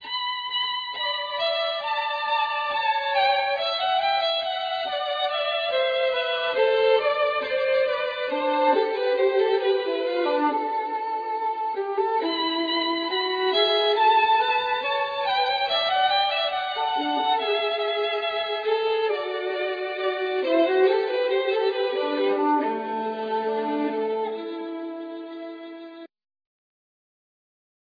Violin, Voice